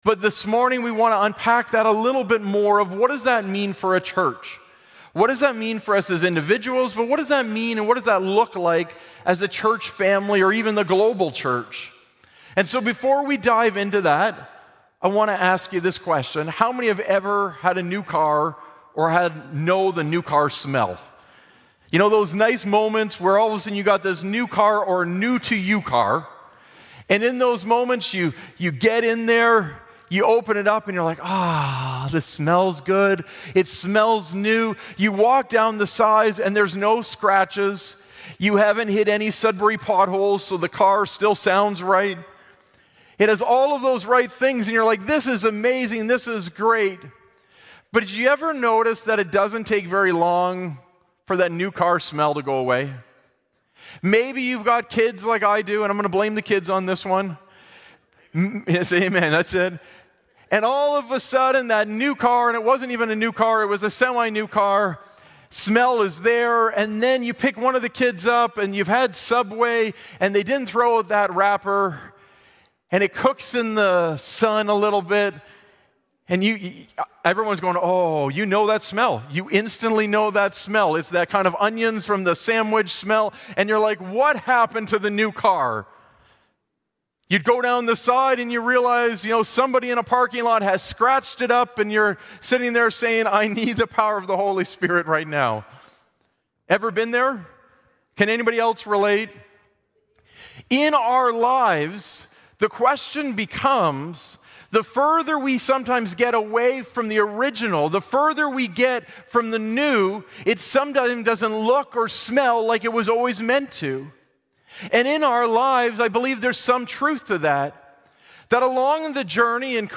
Sermon Podcast